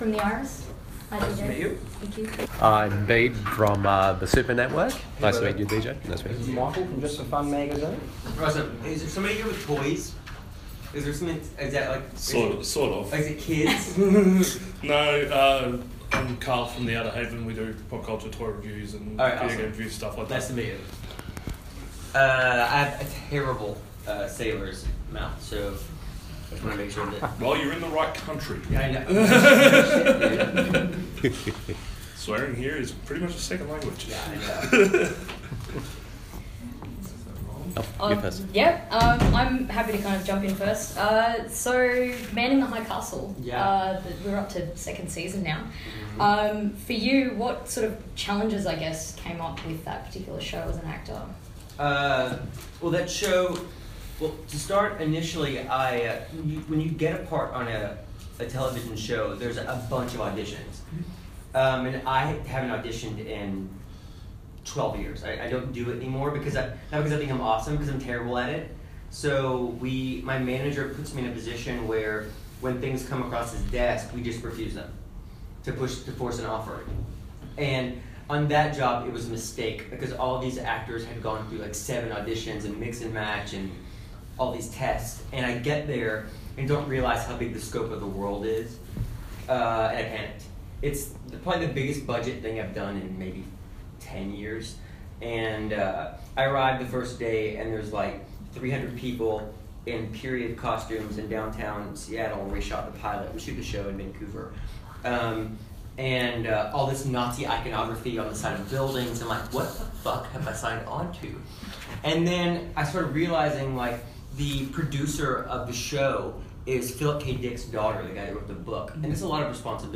[Oz Comic Con 2017] Interview with DJ Qualls
Blue Planet PR kindly organised on behalf of OZ Comic Con for us to have an interview with guest of the event DJ Qualls in a media roundtable alongside fellow bloggers/journalists from The Iris, Just4Fun Magazine and The Outer Haven.